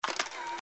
На этой странице собраны различные звуки щелчков пальцами – от четких и звонких до приглушенных и мягких.
9. Затвор фотоапарата